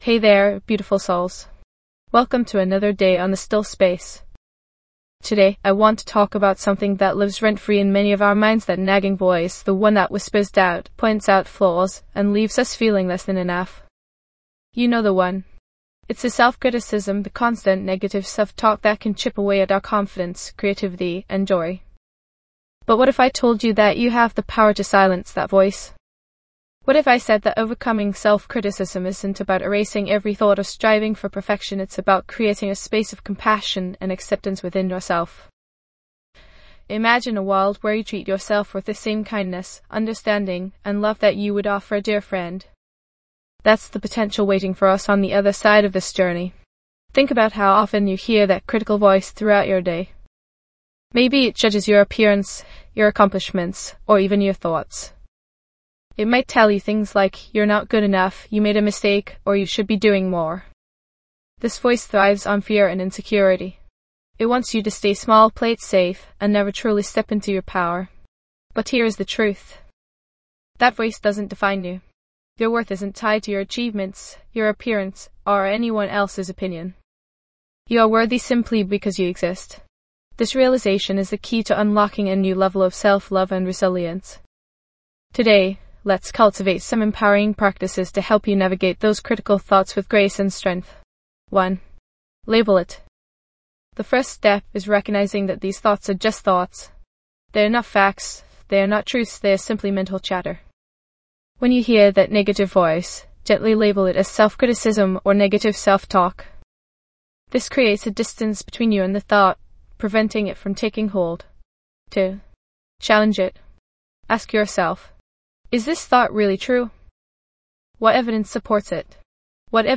Immerse yourself in a guided meditation designed to help you release negative thoughts, boost self-love, and foster a more compassionate relationship with yourself.